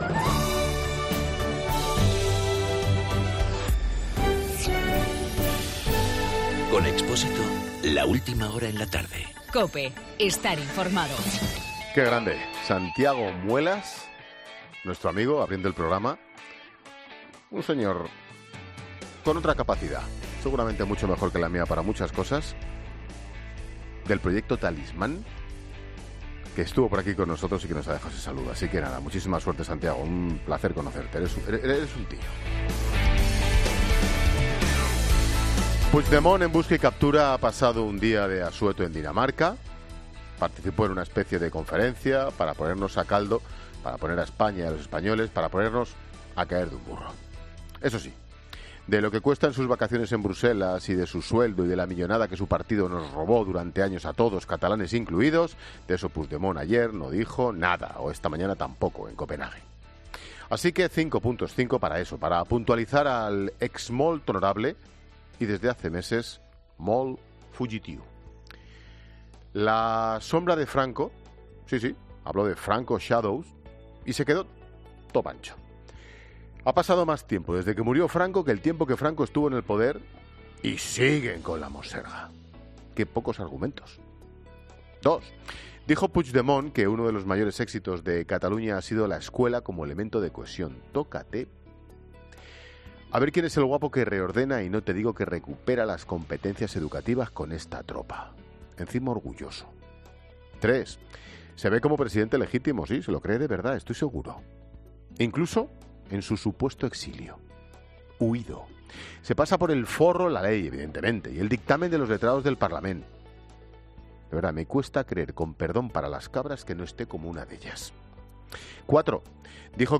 Monólogo de Expósito
El comentario de Ángel Expósito sobre las mentiras de Puigdemont en Dinamarca.